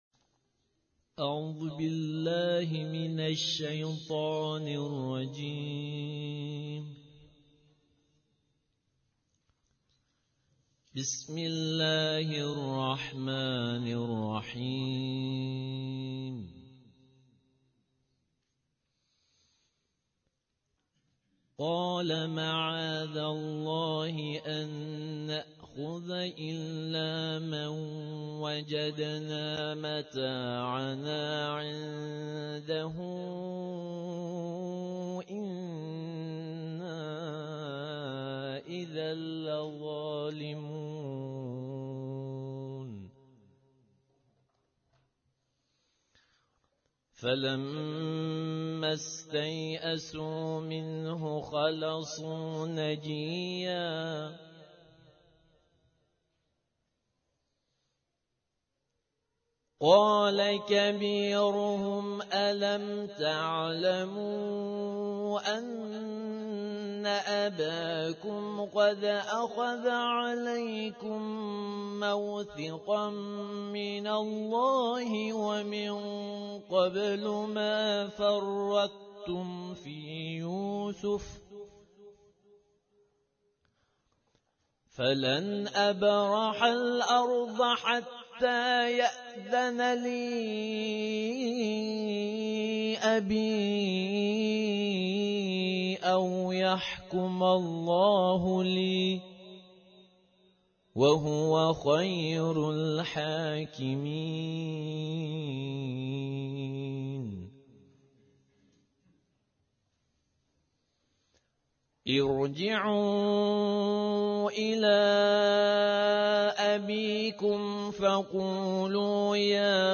در این بخش می توانید فایل صوتی بخش های مختلف “دویست و چهل و پنجمین کرسی تلاوت و تفسیر قرآن کریم” شهرستان علی آباد کتول که در تاریخ ۰۷/آبان ماه/۱۳۹۸ برگزار شد را دریافت نمایید.
ترتیل صفحه ی شریفه ۲۴۵ قرآن کریم